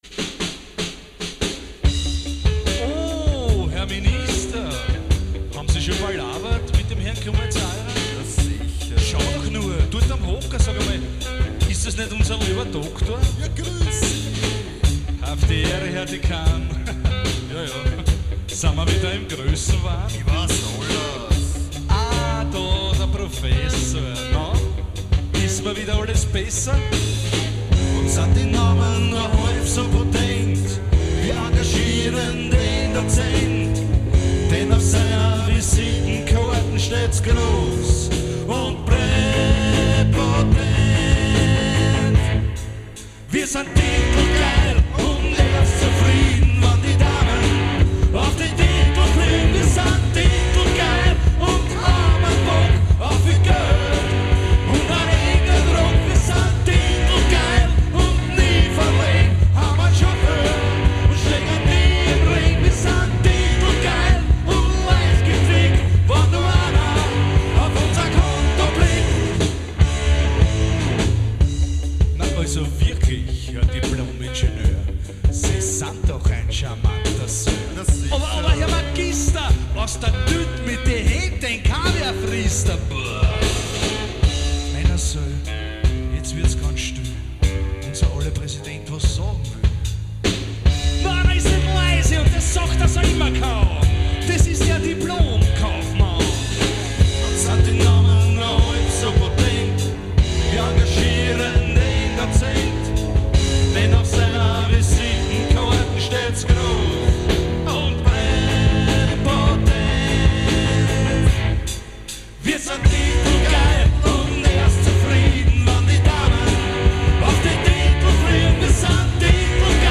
Genre:   Freie Musik - Austro-Pop